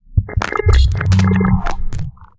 UI_SFX_Pack_61_4.wav